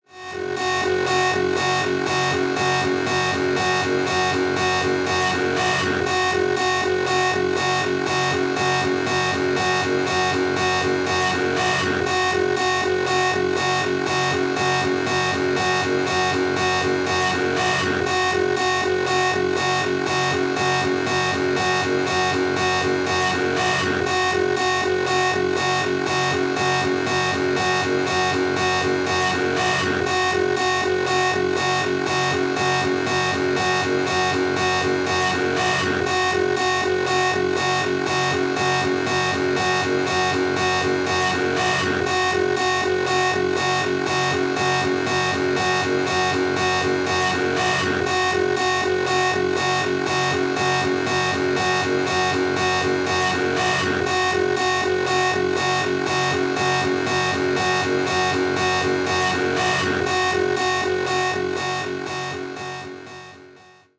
CCSS - Scene 012 - STEM - Mid Alarm.wav